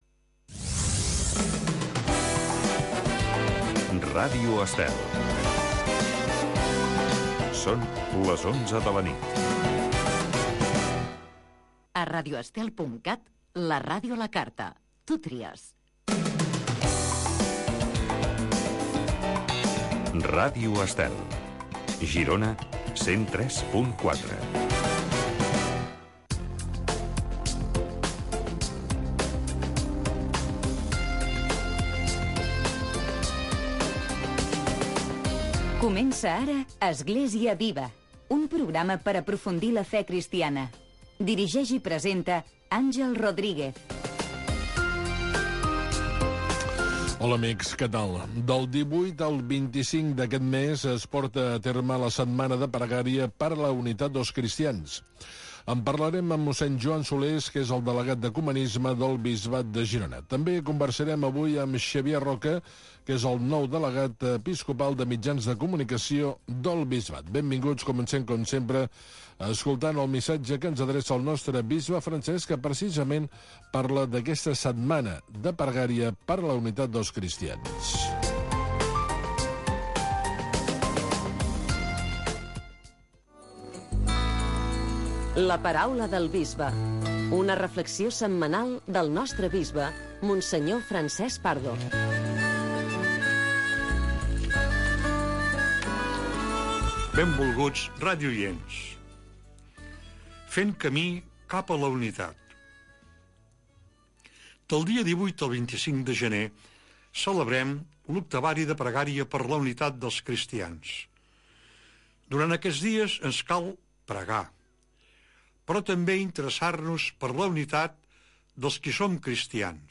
Església viva. Magazine d’actualitat cristiana del bisbat de Girona.